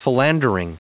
Prononciation du mot philandering en anglais (fichier audio)